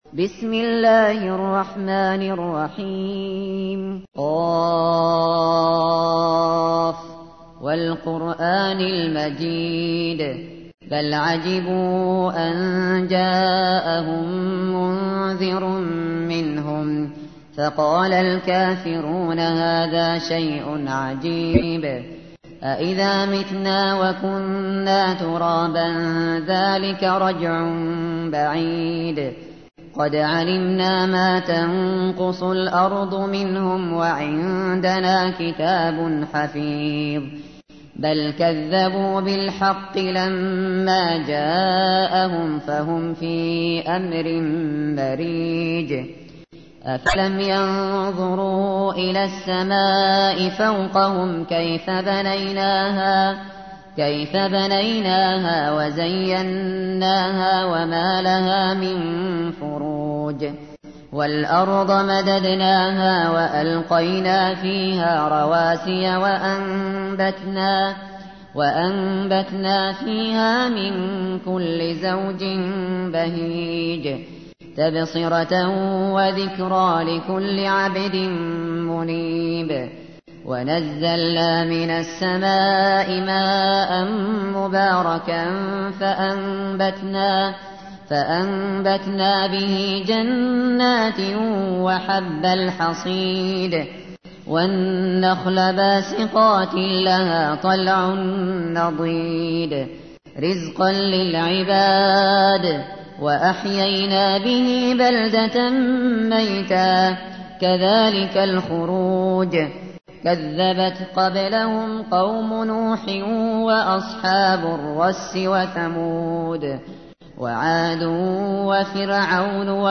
تحميل : 50. سورة ق / القارئ الشاطري / القرآن الكريم / موقع يا حسين